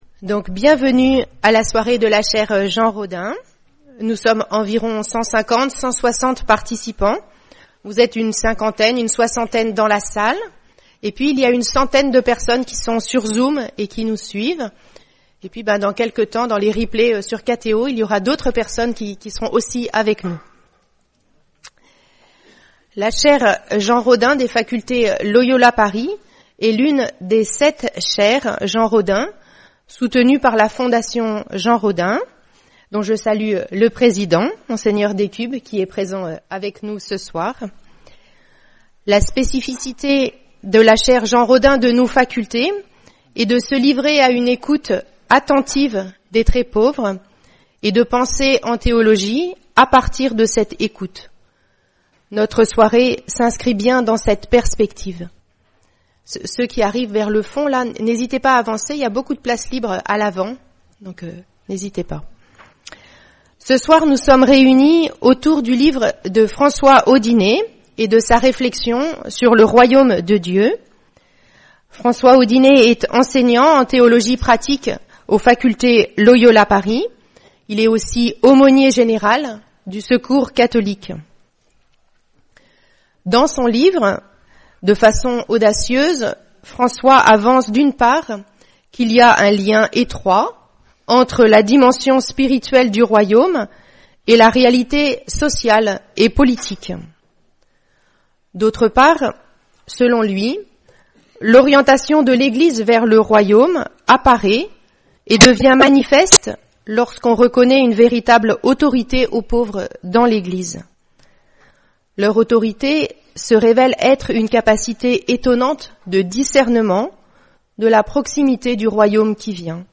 Soirée de la Chaire Jean RODHAIN